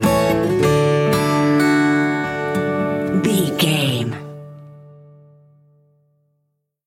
Ionian/Major
acoustic guitar